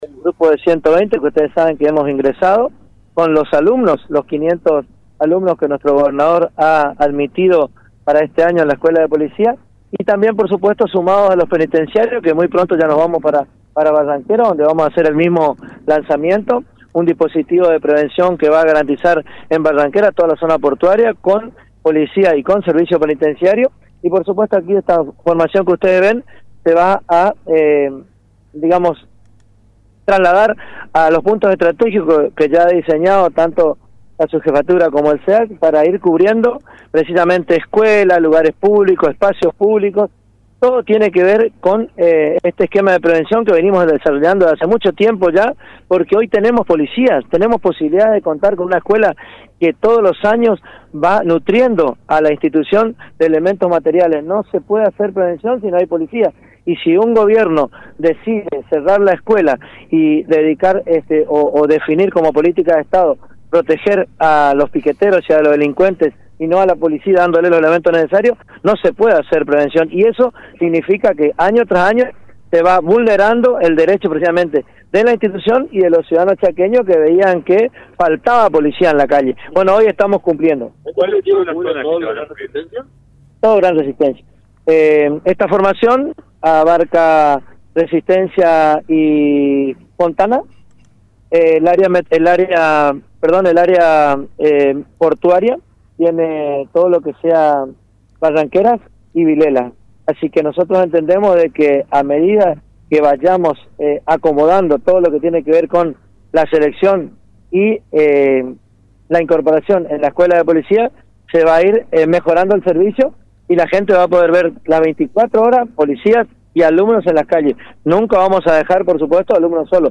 El ministro de Seguridad del Chaco, Hugo Matkovich, encabezó el acto de presentación de un nuevo dispositivo de seguridad preventiva que se desplegará en el Gran Resistencia. En un acto acompañado por efectivos policiales y alumnos de la Escuela de Policía, Matkovich destacó el compromiso del gobierno provincial para fortalecer la seguridad en todo el territorio chaqueño, con una fuerte apuesta a la formación y al despliegue territorial.